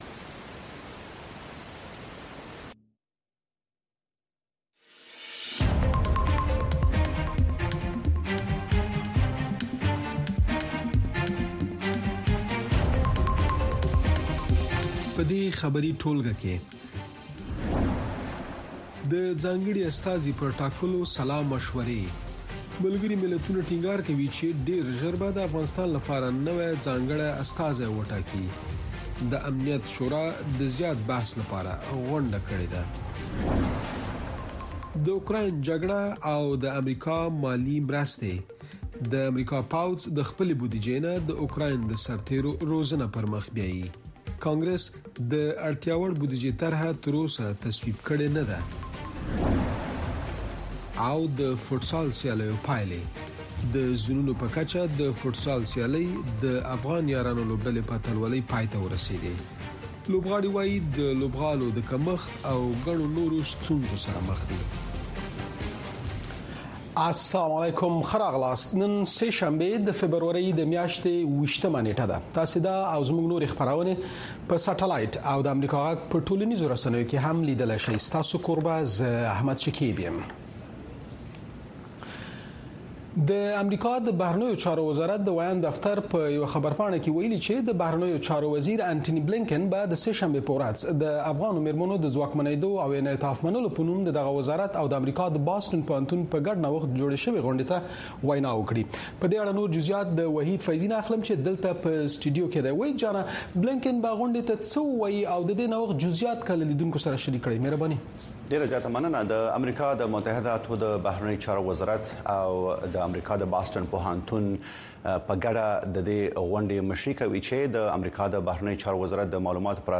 د اشنا په خبري خپرونو کې د شنبې څخه تر پنجشنبې پورې د افغانستان، سیمې او نړۍ تازه خبرونه، او د ټولې نړۍ څخه په زړه پورې او معلوماتي رپوټونه، د مسولینو او کارپوهانو مرکې، ستاسې غږ او نور مطالب د امریکاغږ راډیو، سپوږمکۍ او ډیجیټلي شبکو څخه لیدلی او اوریدلی شی.